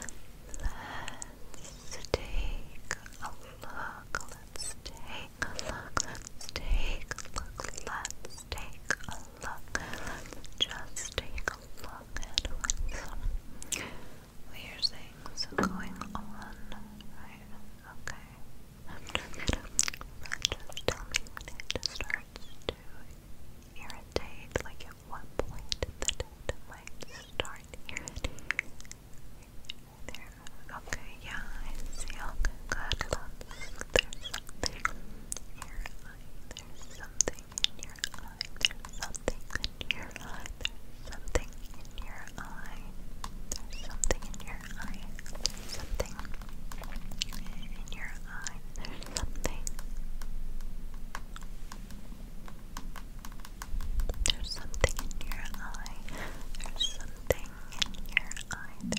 Hair wash & style